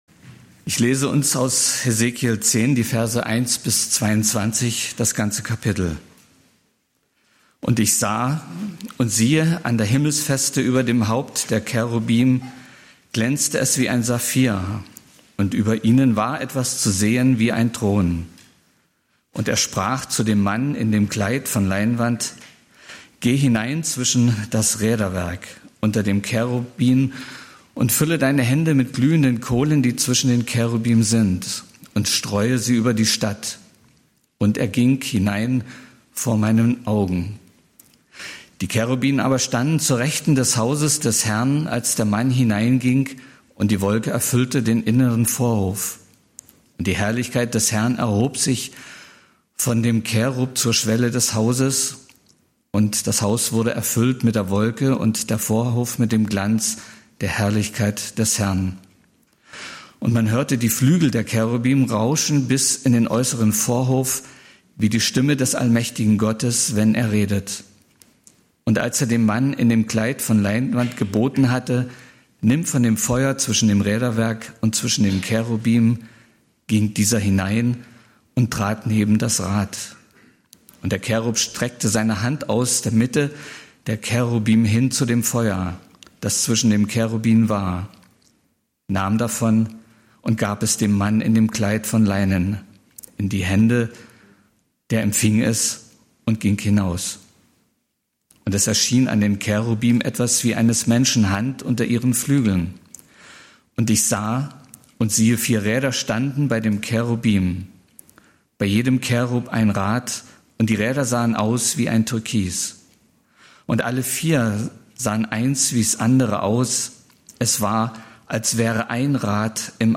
Die große Geschichtsprophetie (Dan. 11, 2b - 12, 4) - Gottesdienst